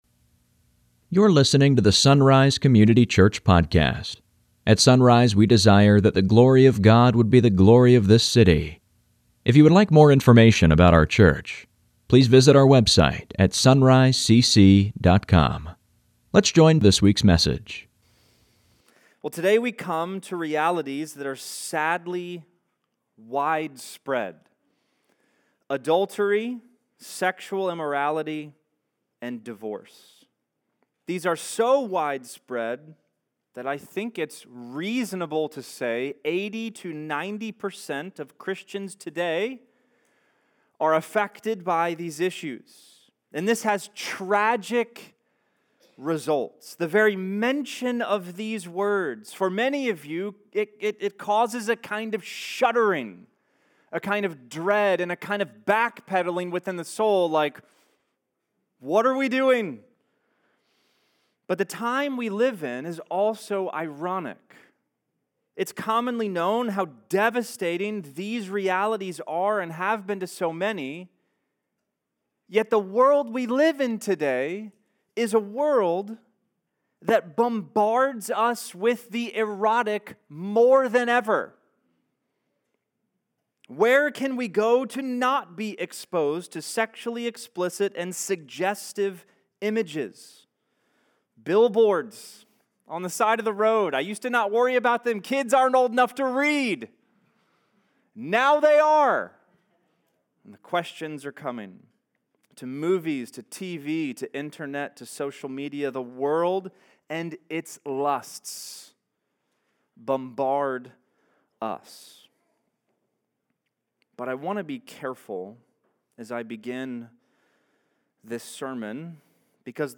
Sunday Mornings | SonRise Community Church
But I want to be careful as I begin a sermon on this topic, because the problem isn’t just out there in the world, it’s in here in the Church.